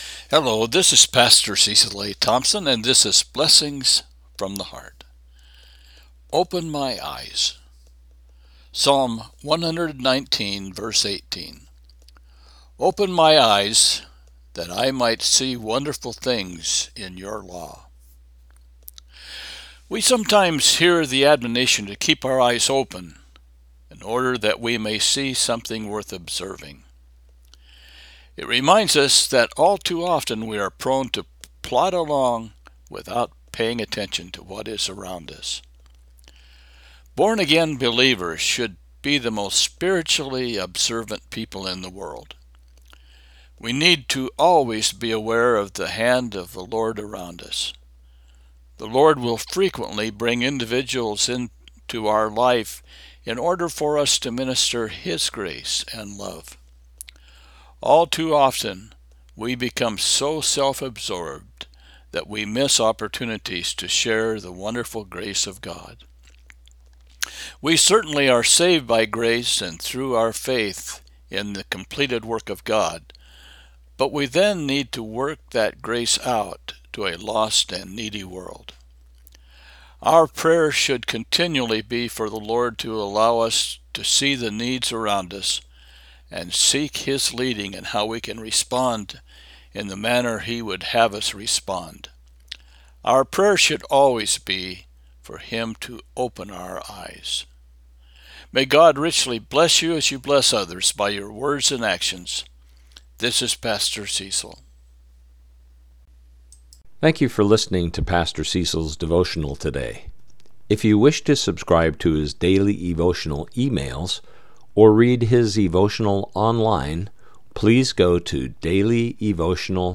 Psalm 119:18 – Devotional